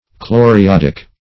Search Result for " chloriodic" : The Collaborative International Dictionary of English v.0.48: Chloriodic \Chlor`i*od"ic\, a. Compounded of chlorine and iodine; containing chlorine and iodine.